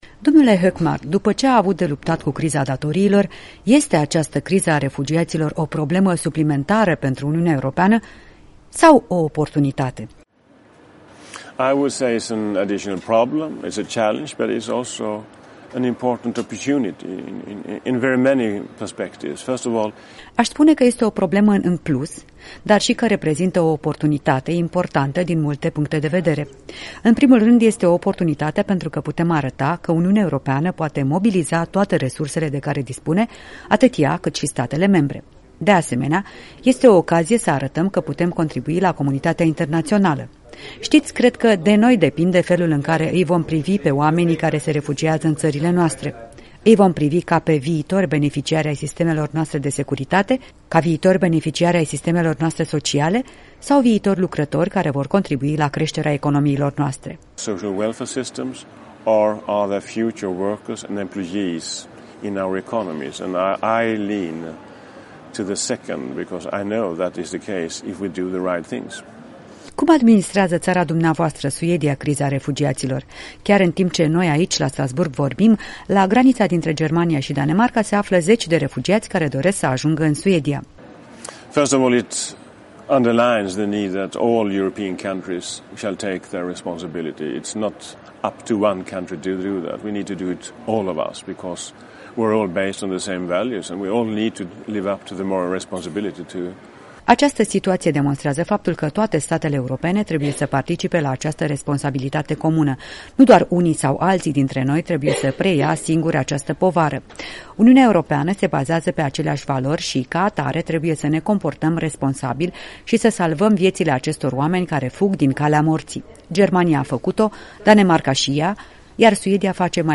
Criza refugiaților - o discuție cu europarlamentarul Gunnar Hökmark